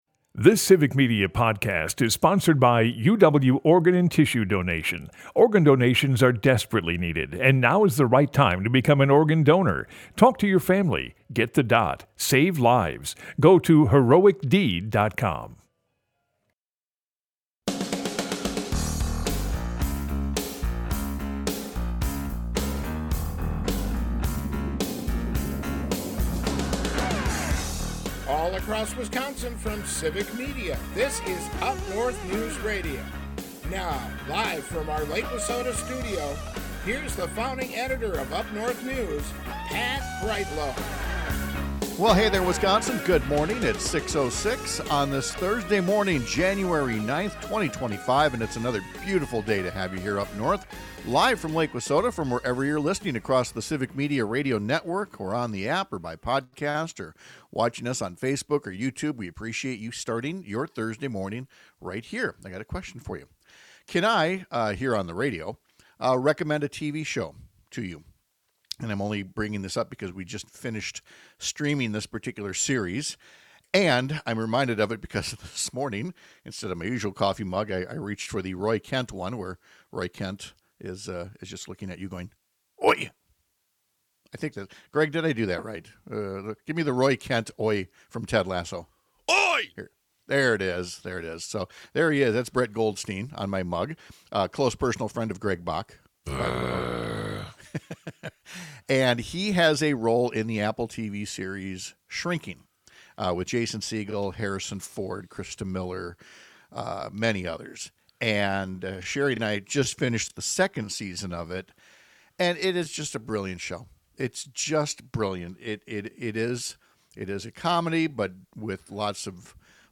Pat Kreitlow is a longtime Wisconsin journalist and former state legislator who lives in and produces his show from along Lake Wissota in Chippewa Falls. UpNorthNews is Northern Wisconsin's home for informative stories and fact-based conversations. Broadcasts live 6 - 8 a.m. across the state!